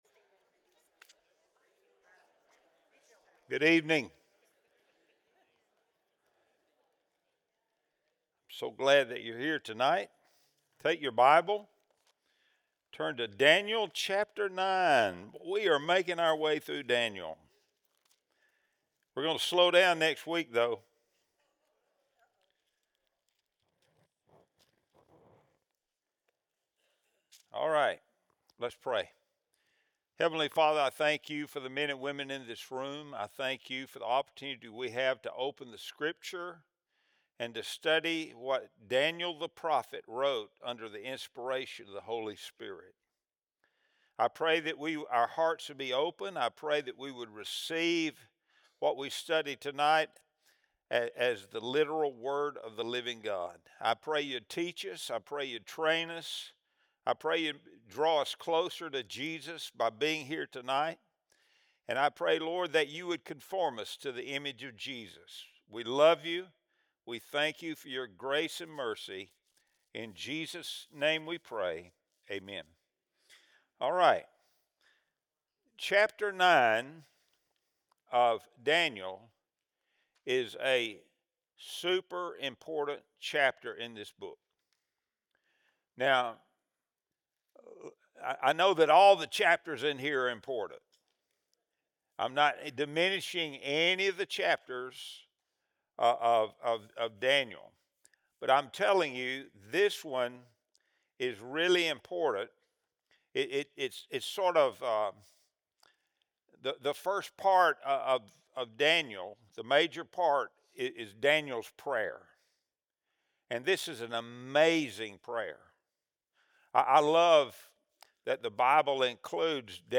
Wednesday Bible Study | December 7, 2025